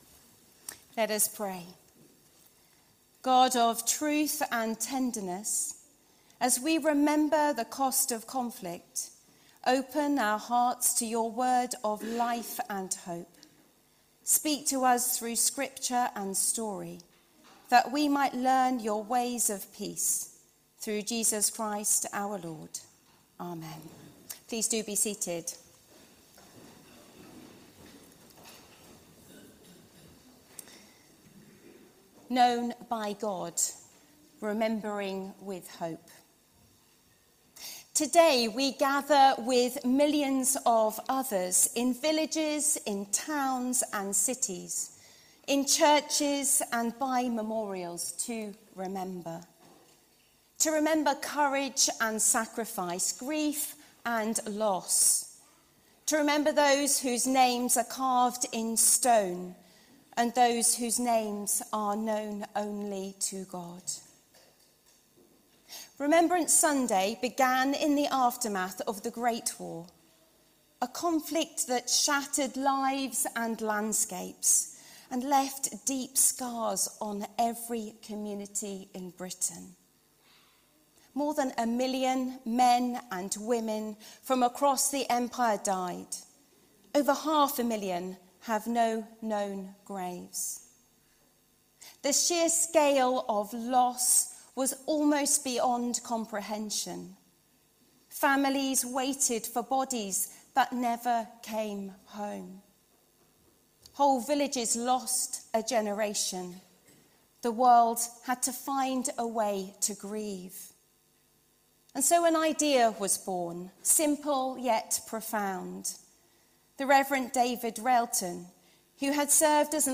Diocese of Oxford